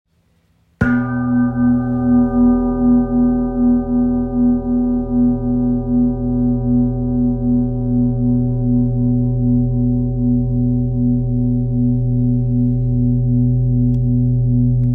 Large Etched Tara Handmade Singing Bowl - 60cm
At 60cm across, it stands as a true centerpiece, producing profound, resonant tones that can fill a room with grounding vibrations. Its size allows the sound to travel further and last longer, making it ideal for group sound baths, meditation sessions, and professional healing work.
• Extra-large 60cm size for deep, room-filling resonance
• Hand-hammered and etched by skilled artisans